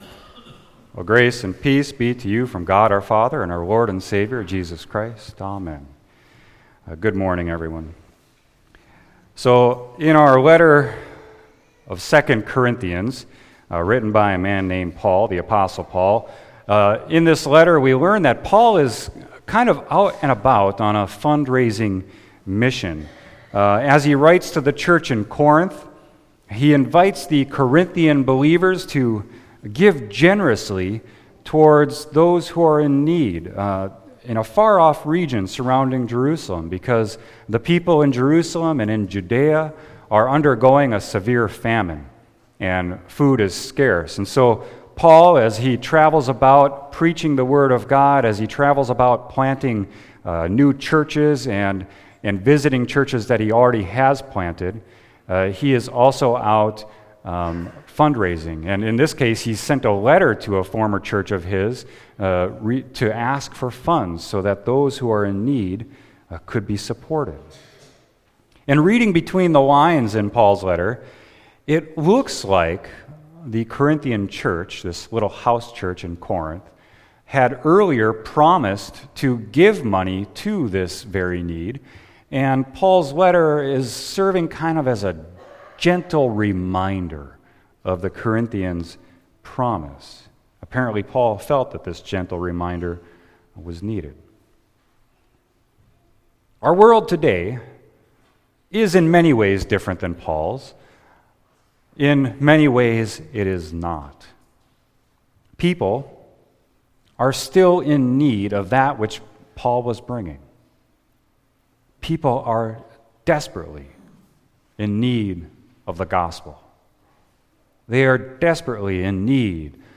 Sermon: 2 Corinthians 9.6-15